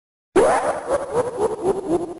File:Sonic laugh.mp3
Sonic_laugh.mp3.ogg